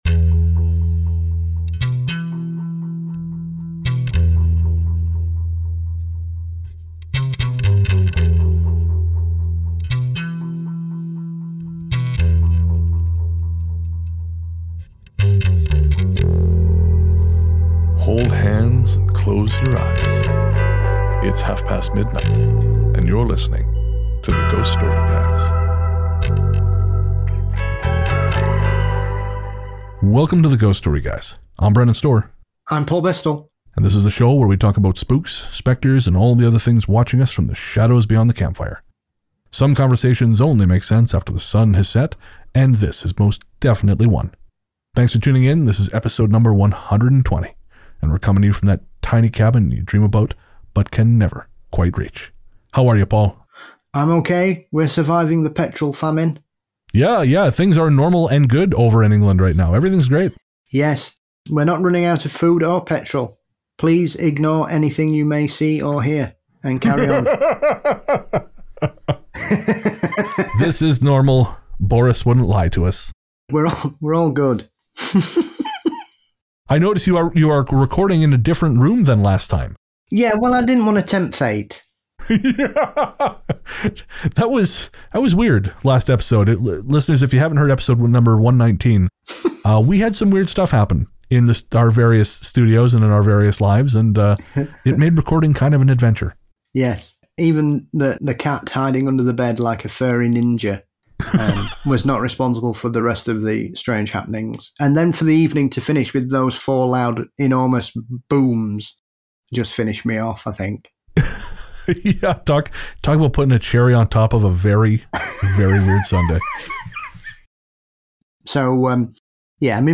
Our musical guest is Vampire Step-Dad